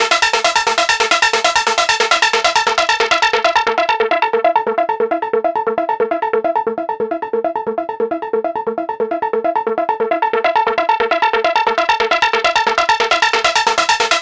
Session 04 - Trance Lead 03.wav